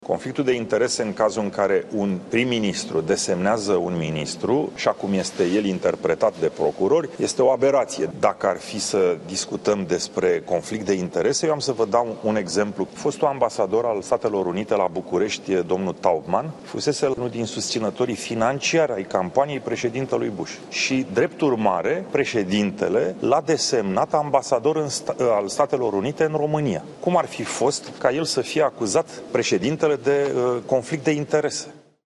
Călin Popescu Tăriceanu a făcut această afirmație într-o conferință de presă în care le explica jurnaliștilor ce presupune imunitatea parlamentarilor.